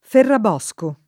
Ferrabosco [ f errab 0S ko ] cogn.